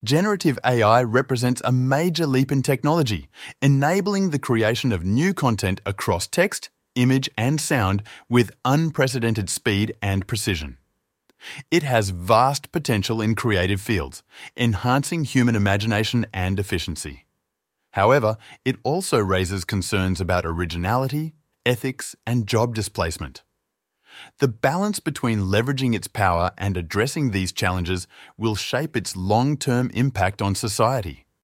テキストから音声を生成する
その後、「Voice」の項目から好みの声を選択して「Generate」をクリックすると音声が生成され画面右側に生成結果が表示されます。
英語Ver.